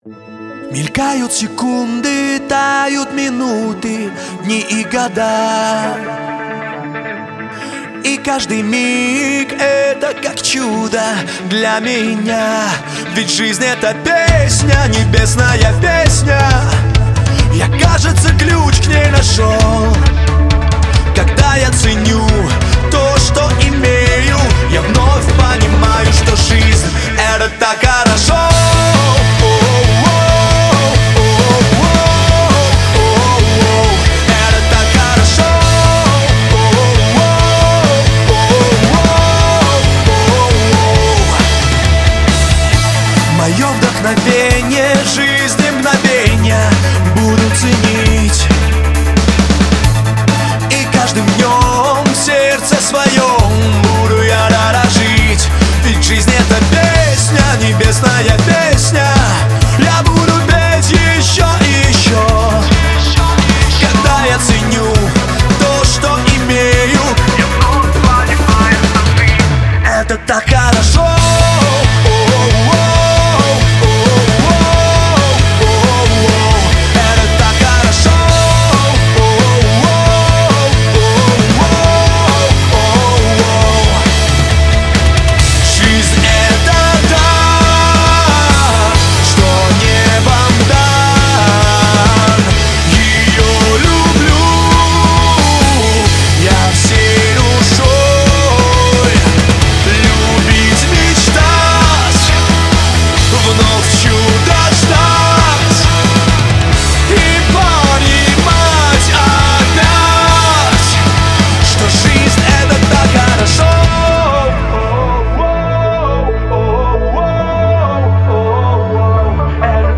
on 2015-12-27 - Молитвенное пение
81069-Zhizn_-_Hristianskie_pesni.mp3